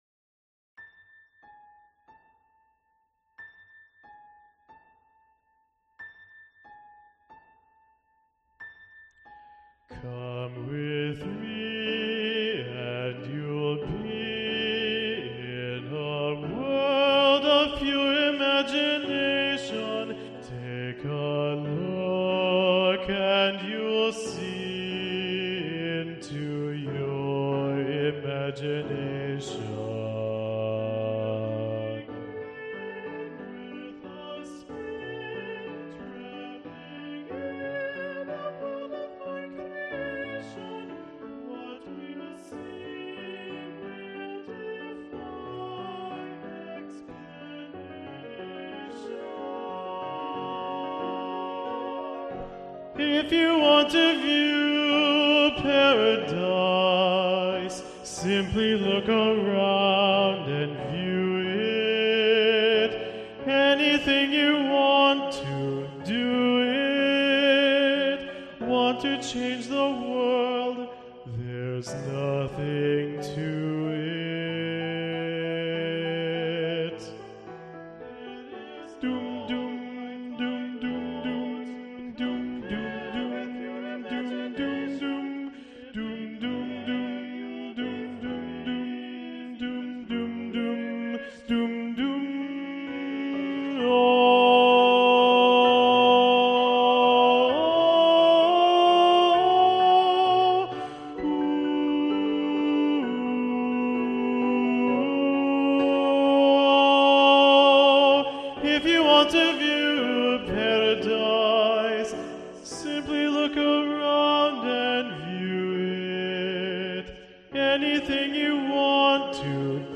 Tenor 2 Predominant